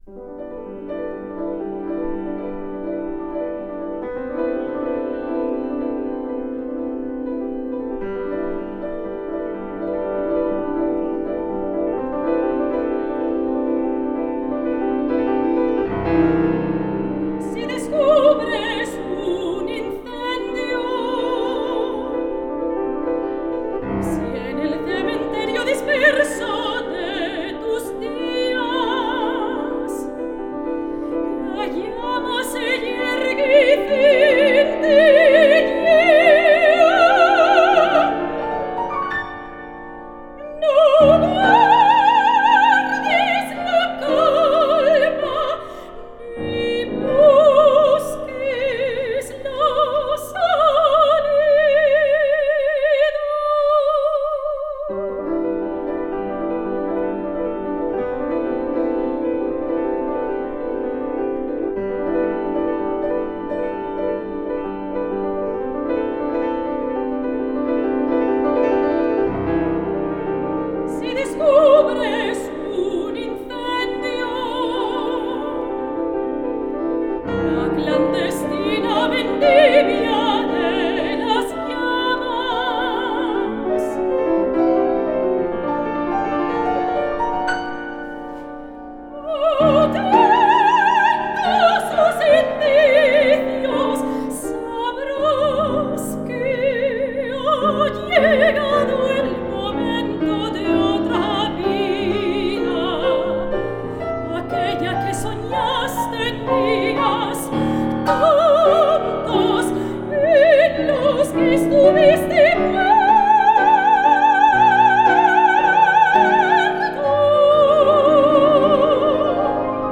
Canción para soprano y piano
Instrumentación: Soprano y piano.